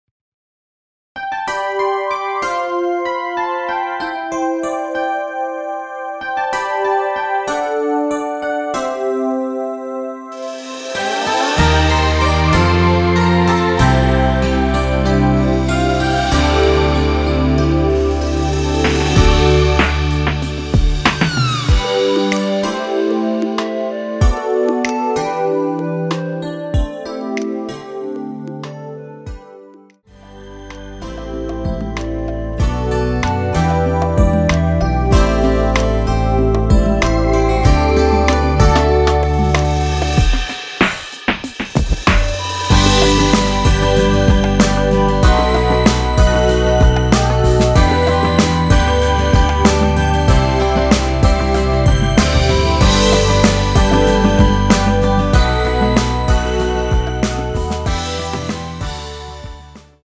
여성분이 부르실수 있는키의 MR입니다.
원키에서(+3)올린 멜로디 포함된 MR입니다.(미리듣기 참고)
앞부분30초, 뒷부분30초씩 편집해서 올려 드리고 있습니다.
중간에 음이 끈어지고 다시 나오는 이유는